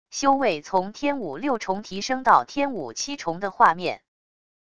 修为从天武六重提升到天武七重的画面wav音频